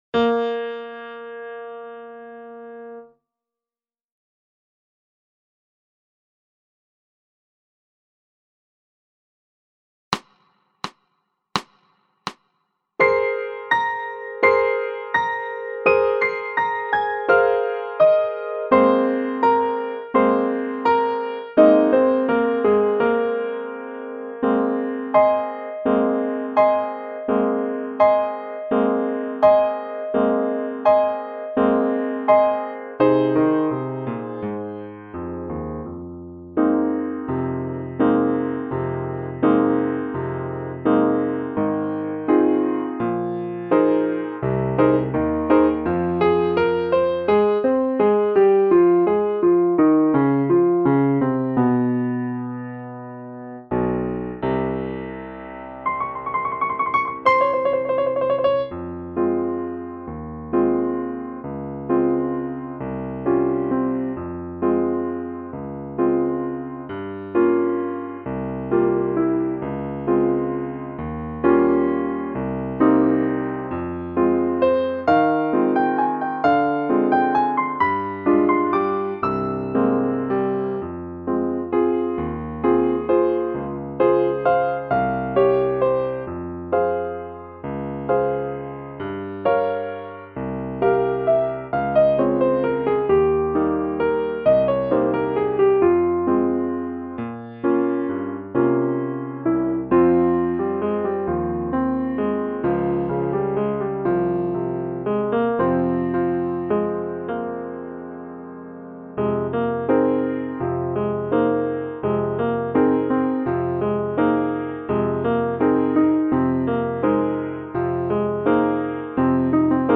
SAXOPHONE-Un_petit_chinois_à_Dinant.mp3